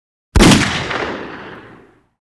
Звуки огнестрельного оружия
Громкий выстрел пистолета Magnum 357